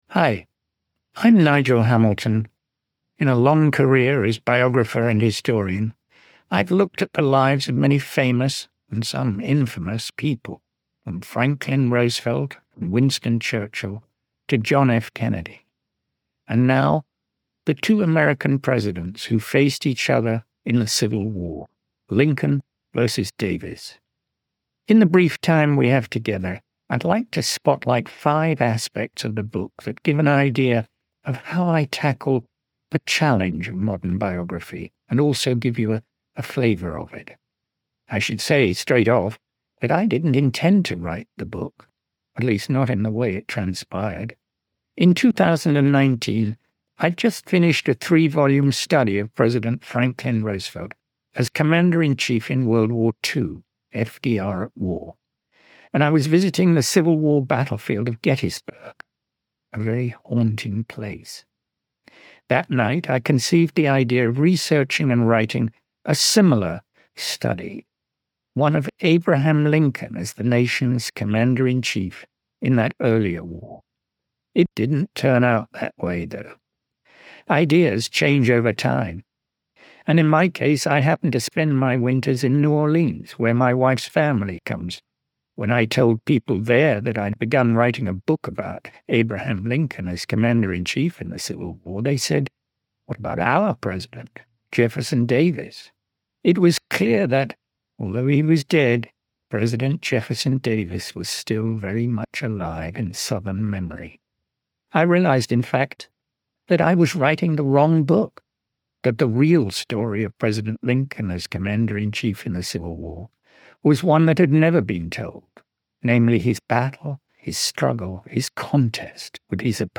Listen to the audio version—read by Nigel himself—in the Next Big Idea App.